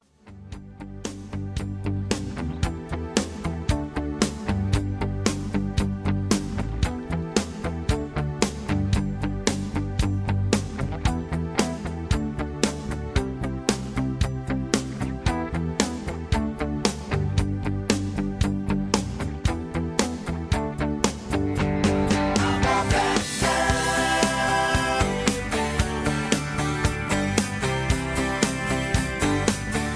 (Version-3, Key-G) Karaoke MP3 Backing Tracks
Just Plain & Simply "GREAT MUSIC" (No Lyrics).